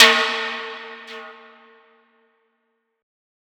Perc  (11).wav